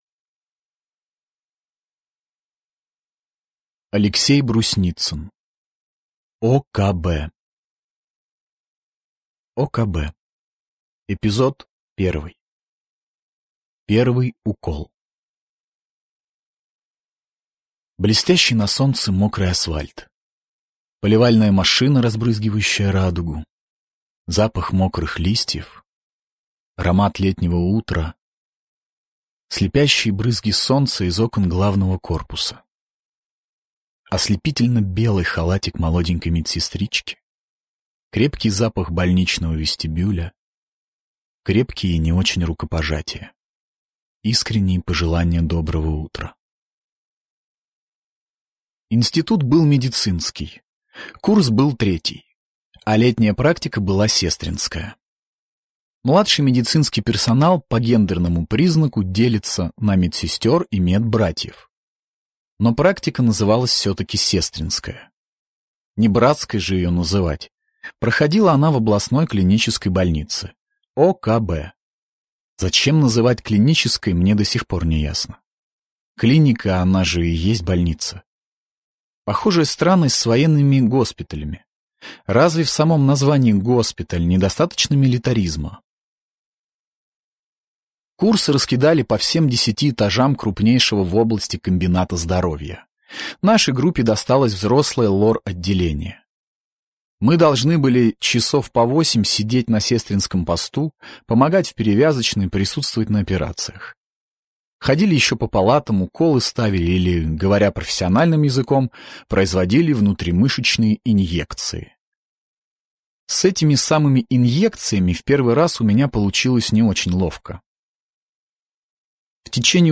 Аудиокнига ОКБ | Библиотека аудиокниг